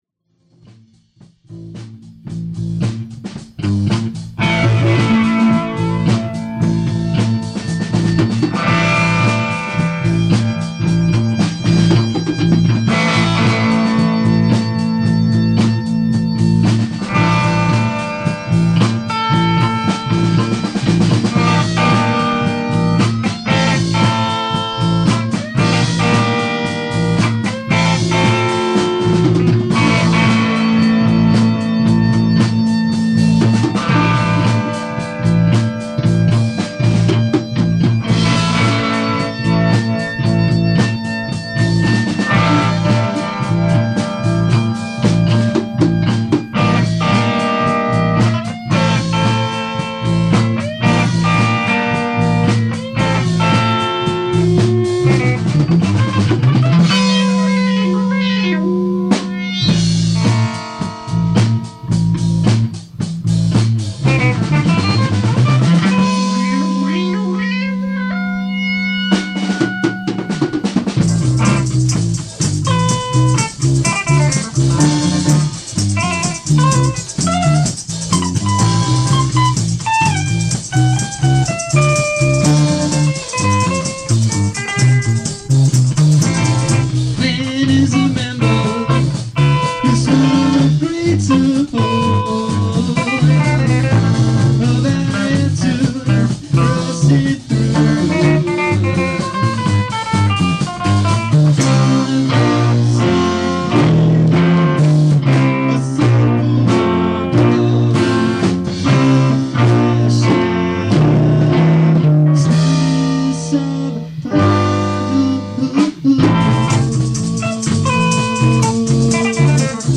The group recorded the rehearsal onto cassette tape.
percussion
bass guitar, vocals
keyboards
guitar, vocals